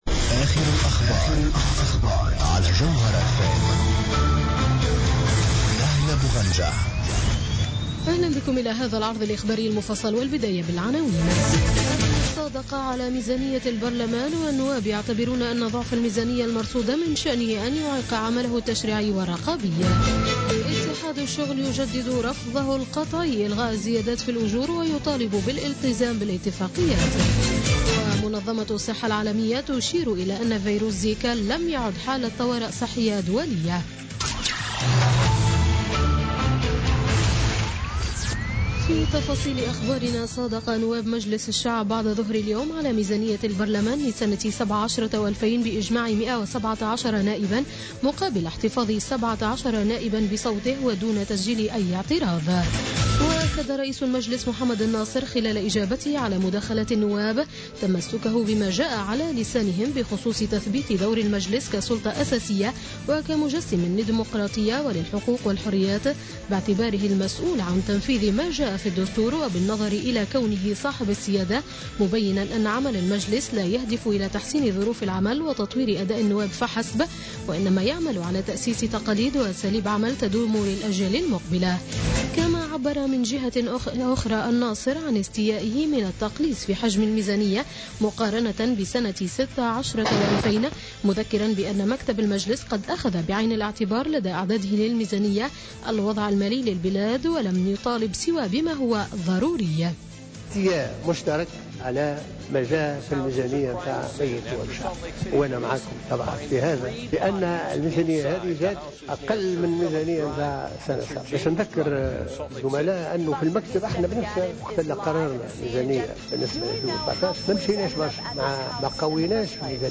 نشرة أخبار السابعة مساء ليوم السبت 19 نوفمبر 2016